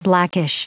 Prononciation du mot blackish en anglais (fichier audio)
Prononciation du mot : blackish